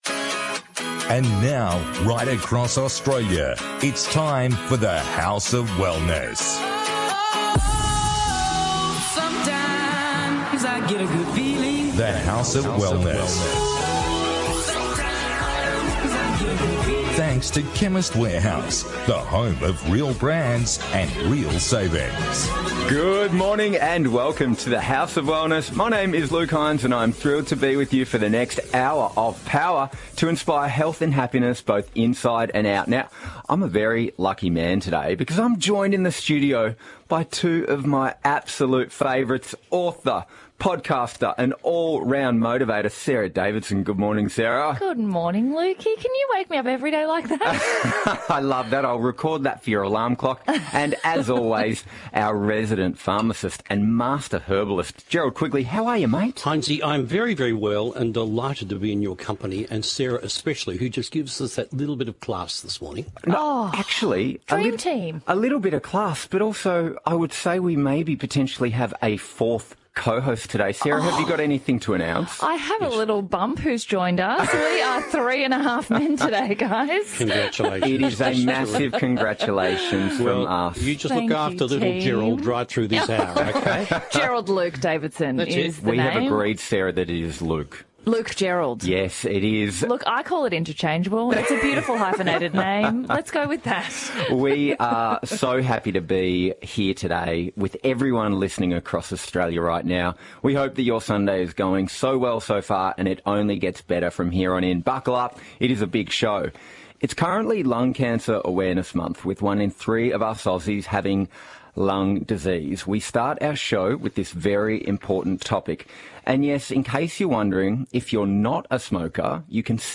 On The House of Wellness Radio show this week, an in-depth discussion on lung cancer in Australia, the skincare routine to put your best face forward this party season.